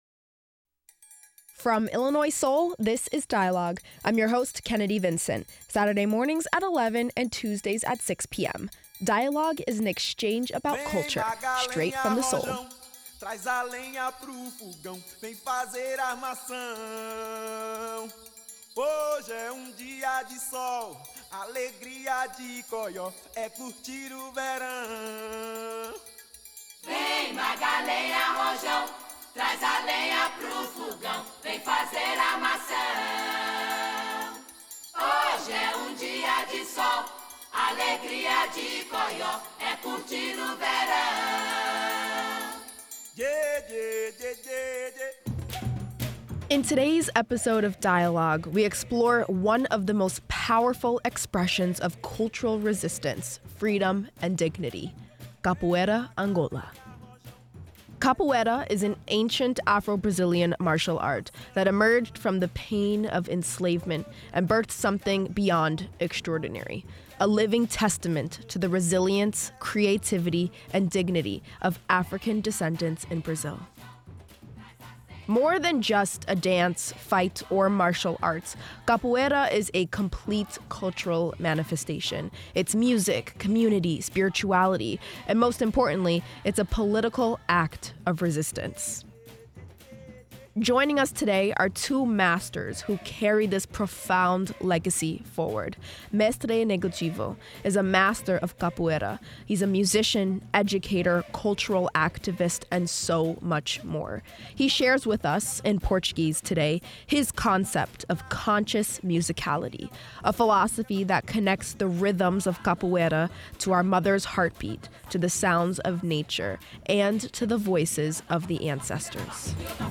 Two masters of Capoeira Angola and a translator explore how Afro-Brazilian martial art continues to be a form of cultural resistance and spiritual nourishment.